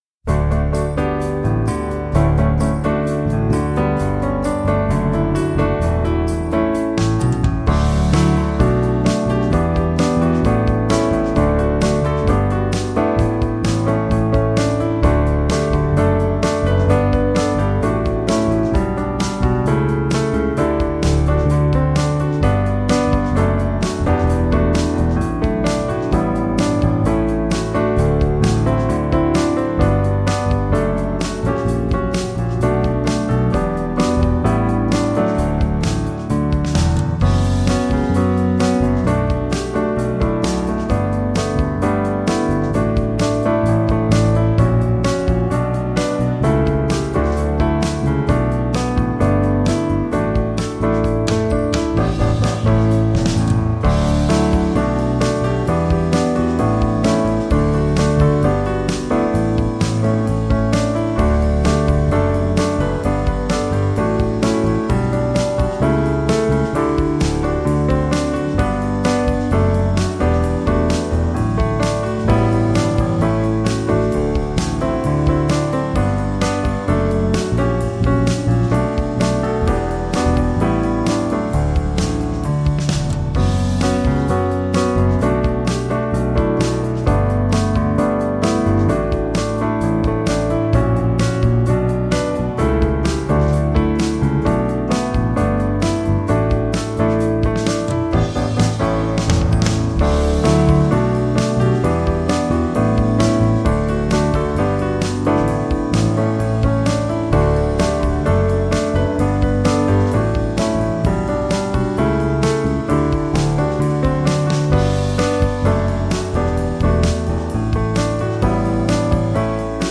Musik Instrumental